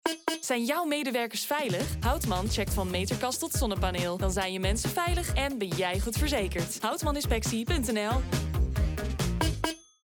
Jong, Natuurlijk, Speels, Toegankelijk, Vriendelijk
Commercieel
Equipped with a professional home studio, she delivers high-quality recordings with a fast turnaround, ensuring both efficiency and excellence in every project.